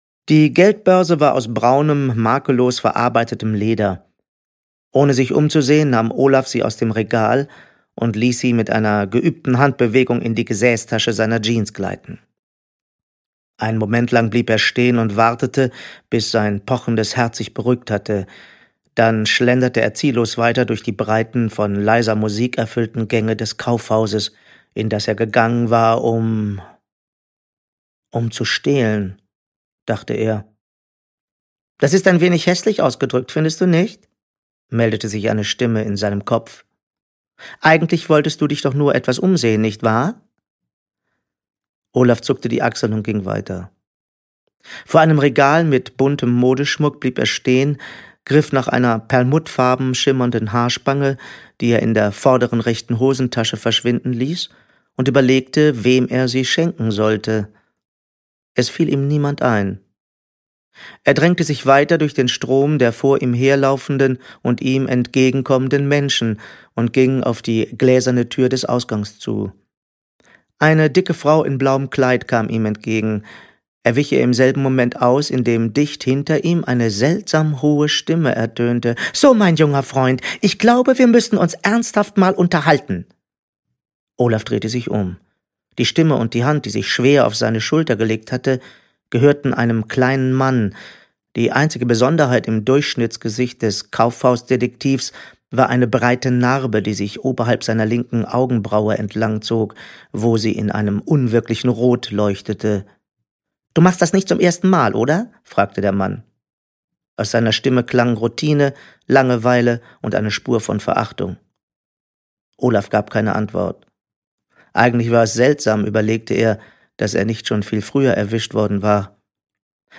Beschützer der Diebe - Andreas Steinhöfel - Hörbuch